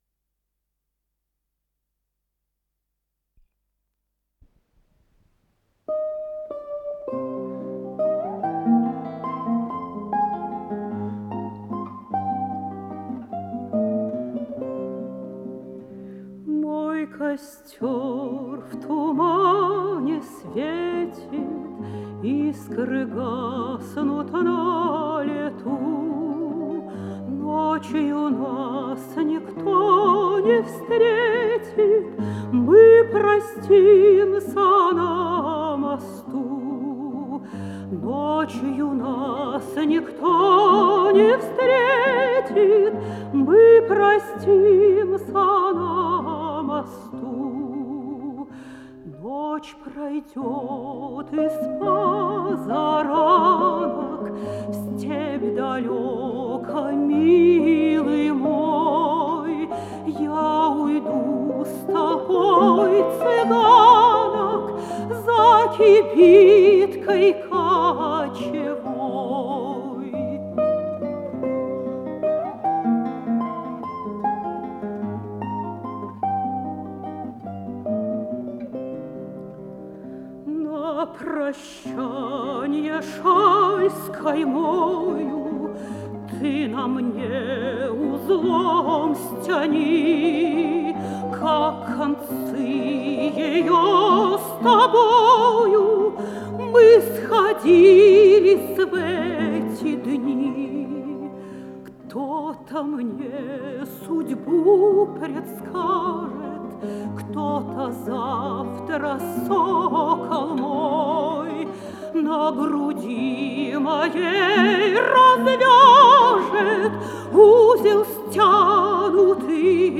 меццо-сопрано
балалайка
гитара